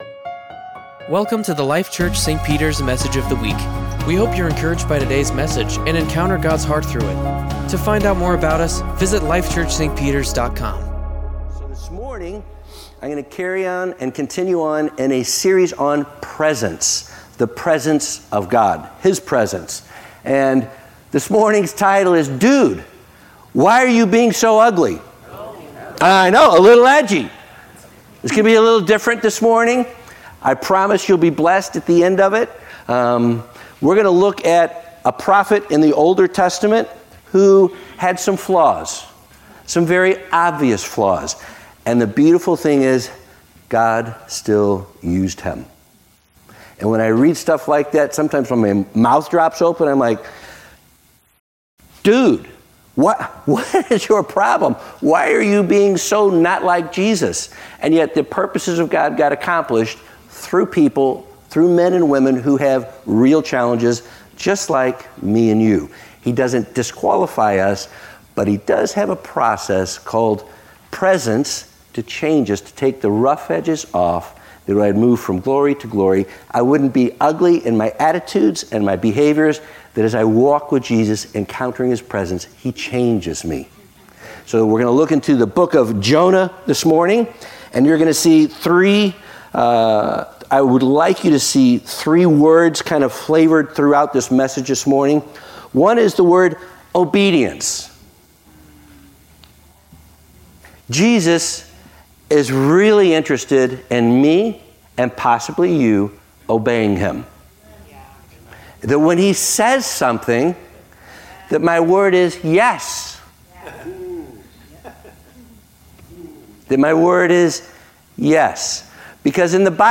Sermons | Life Church - St. Peters